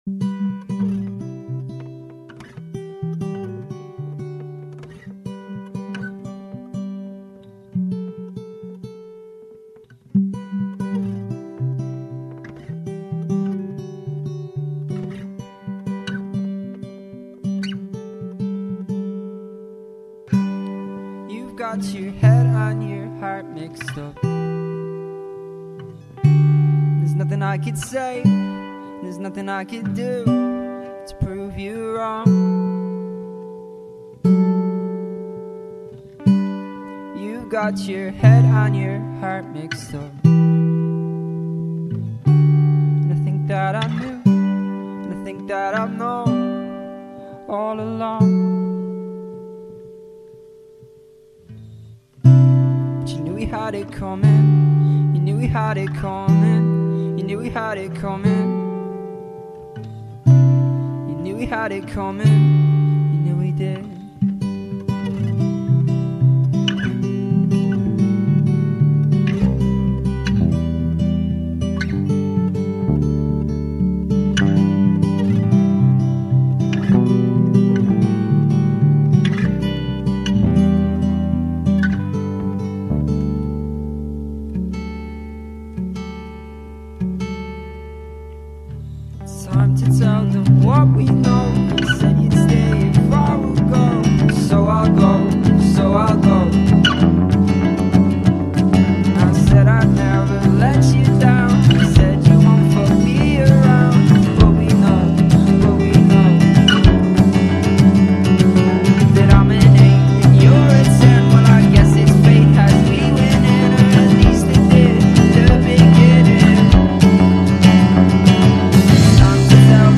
dreiköpfige, irische folk-band